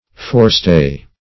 Forestay \Fore"stay`\, n. (Naut.)